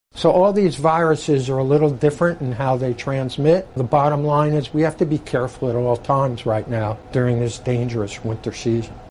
Infectious disease physician